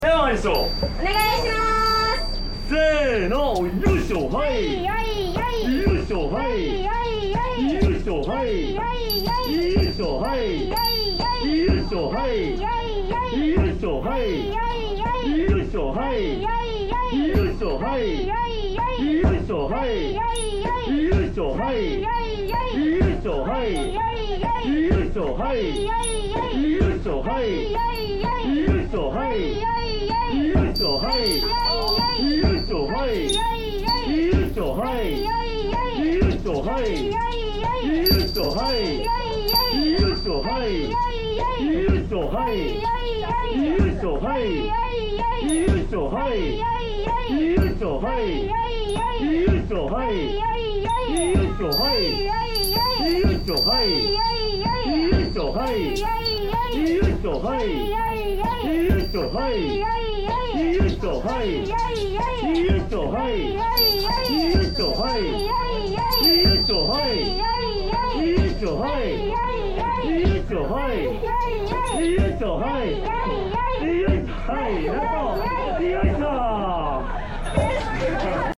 pounds some mochi!